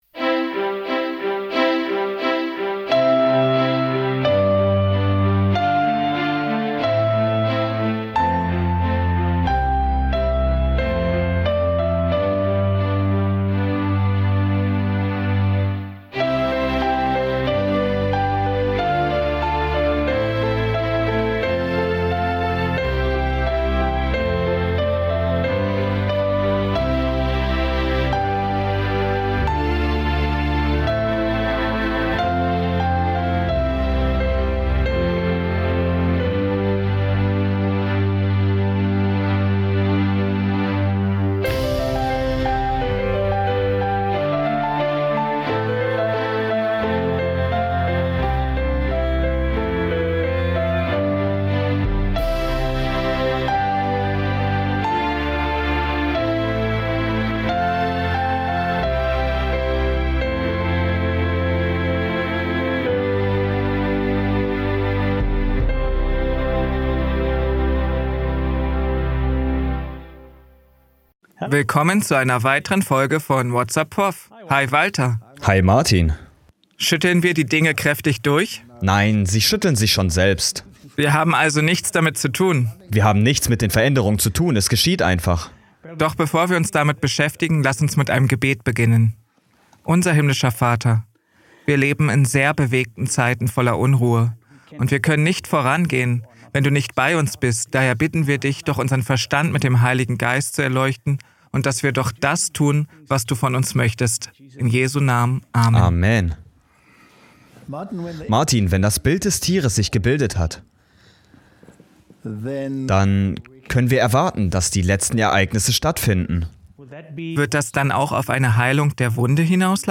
In einem packenden Vortrag wird die drohende Einführung eines Sonntagsgesetzes diskutiert, das tiefgreifende Auswirkungen auf die Gesellschaft und die Religionsfreiheit haben könnte. Der Referent beleuchtet die Verquickung von Politik und Religion und identifiziert Zeichen der Zeit.